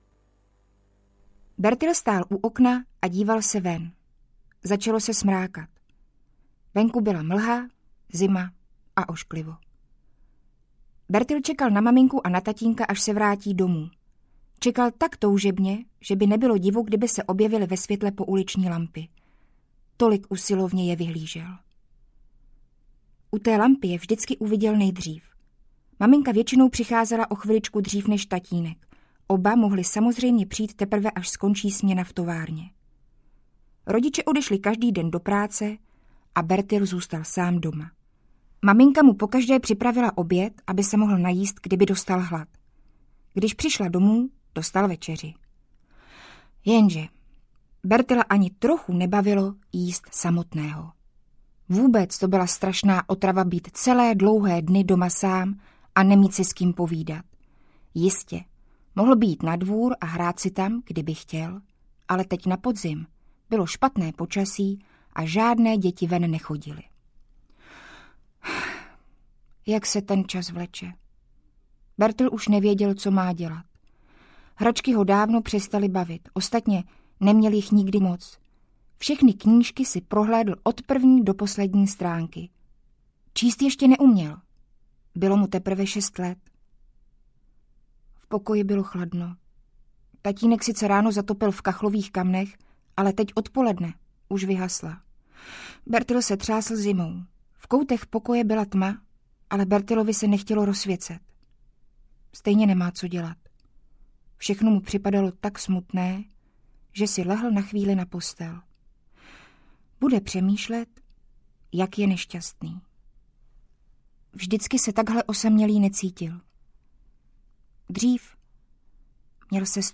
Skřítek Nils audiokniha
Ukázka z knihy
• InterpretLinda Rybová